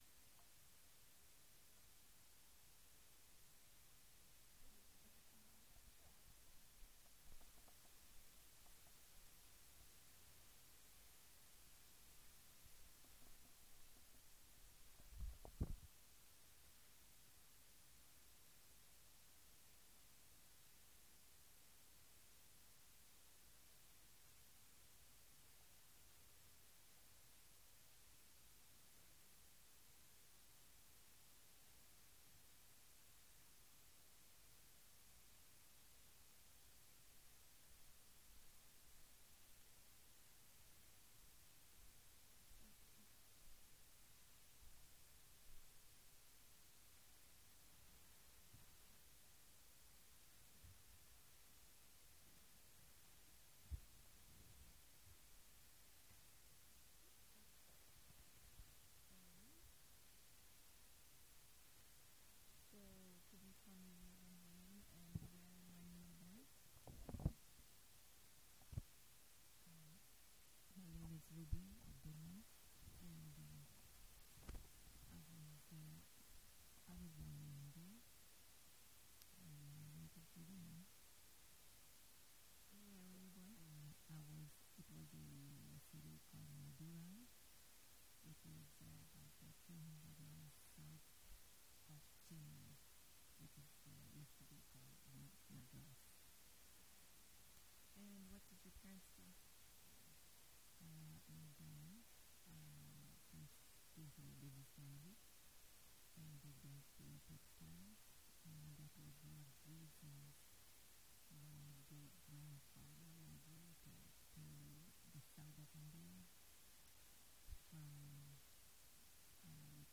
This is part 1 of 3 files constituting the full interview.